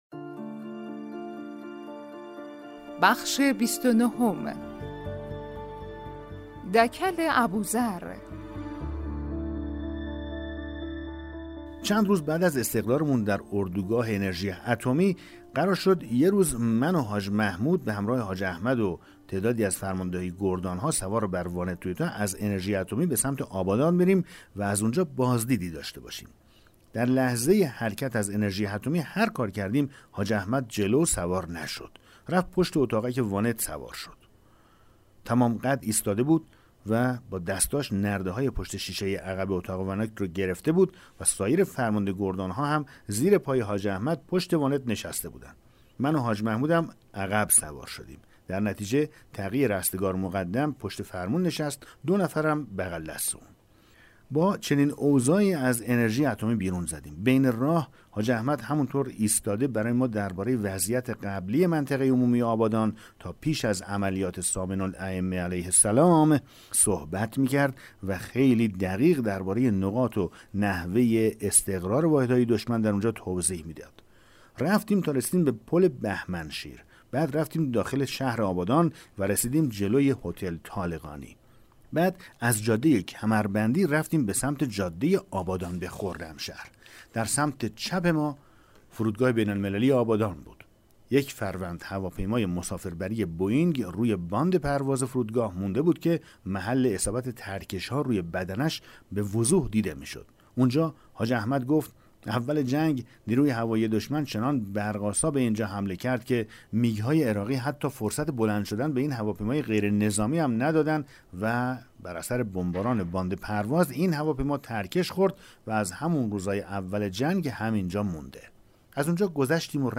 کتاب صوتی پیغام ماهی ها، سرگذشت جنگ‌های نامتقارن حاج حسین همدانی /قسمت 29